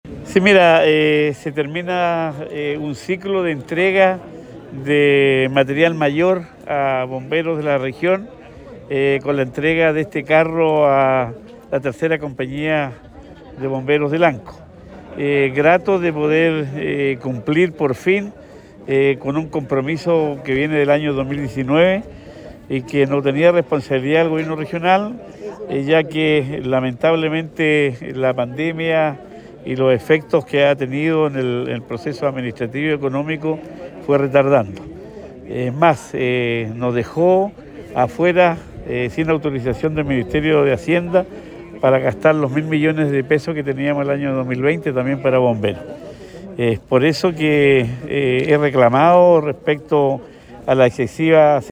En una emotiva ceremonia, el Gobernador Regional de Los Ríos, Luis Cuvertino junto al Consejero Regional Elías Sabat entregaron un nuevo carro a la 3ra Compañía de Bomberos de Lanco, proyecto que tuvo una inversión de $171 millones, de los cuales $55 millones corresponden a un aporte de la Junta Nacional de Bomberos y $116 millones del GORE de Los Ríos, a través del Fondo Nacional de Desarrollo Regional (FNDR).
Cuña_Gobernador_carro-Lanco_1.mp3